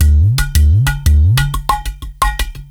Udu_04(90BPM).wav